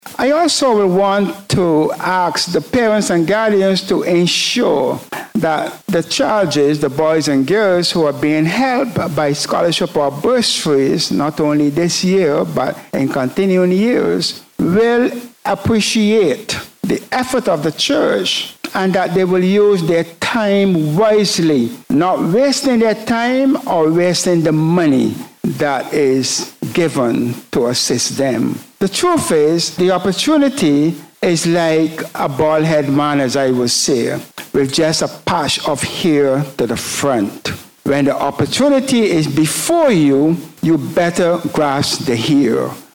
The Methodist Church Kingstown-Chateaubelair Circuit held its 7th annual Scholarship Distribution Ceremony at the Church Hall in Kingstown, earlier this week.